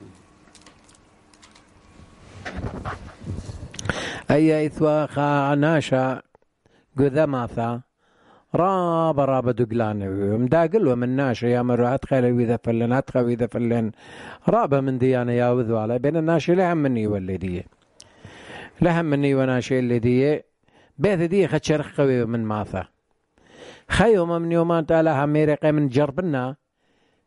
Barwar: The Man Who Cried Wolf